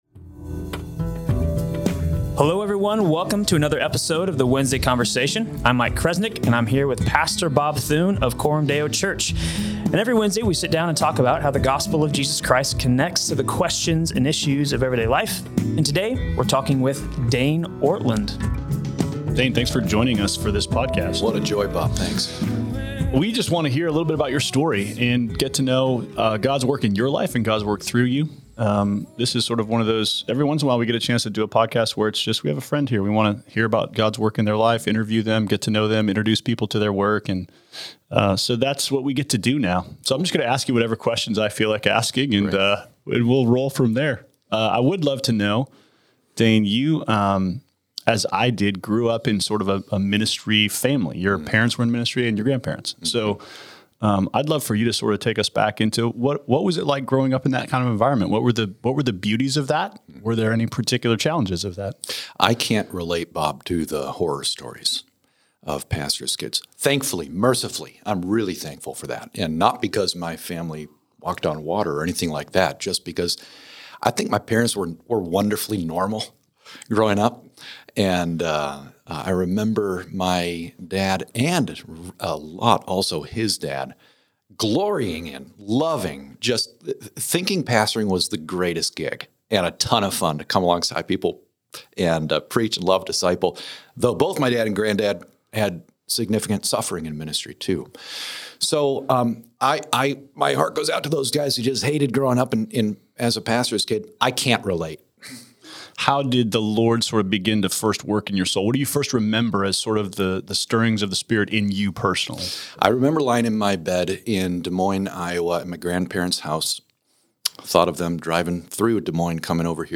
Episode 332: A Conversation